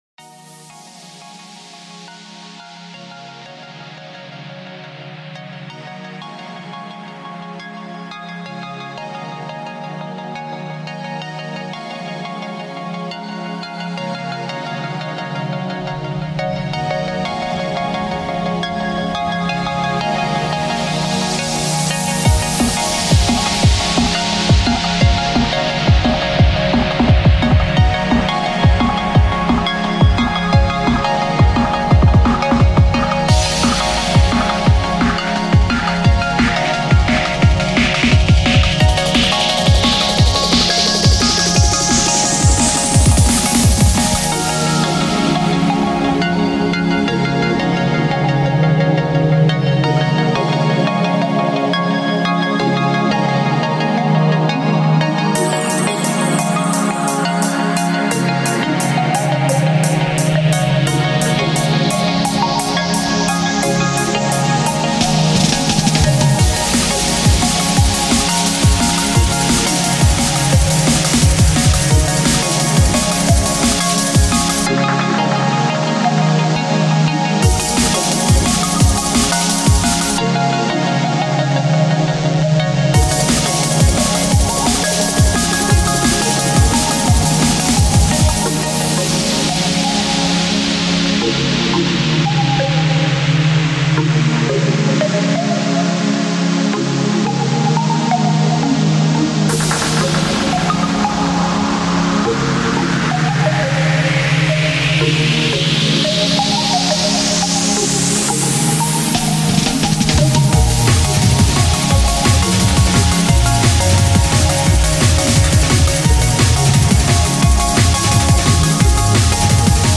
I provide a new, energetic song from the genre liquid dnb.
Genre: Liquid DNB BPM: 174